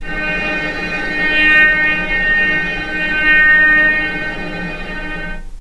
vc_sp-D#4-pp.AIF